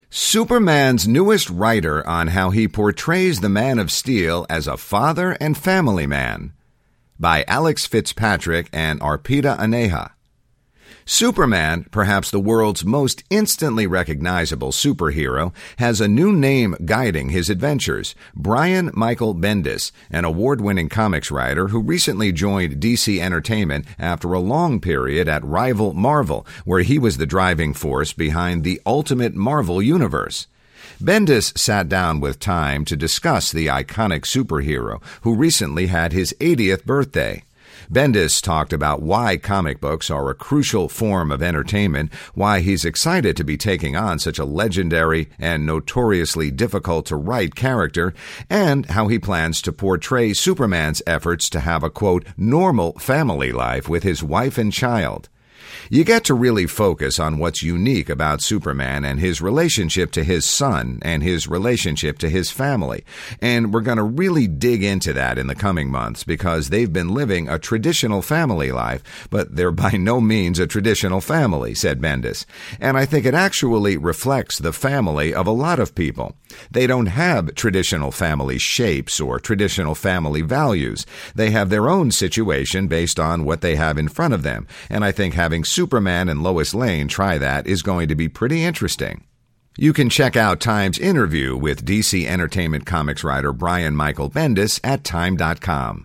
Bendis sat down with TIME to discuss the iconic superhero, who recently had his 80th birthday.